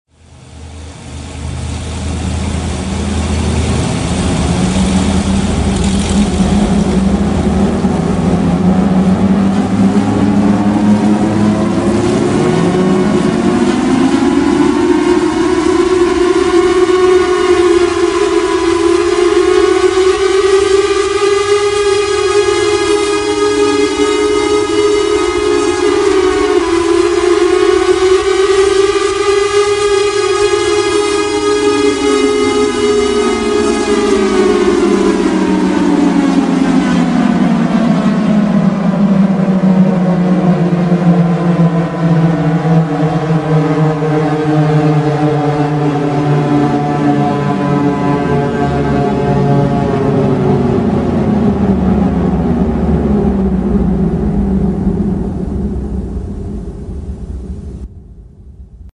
Chrysler Air-raid Siren
The siren had an output of 138 dBs  (30,000 watts), and could be heard as far as 25 miles away.
(Be aware that NO computer speakers can come close to reproducing this siren sound)
The Chrysler air raid siren produced the loudest sound ever achieved by an air raid siren
ChryslerSiren2.mp3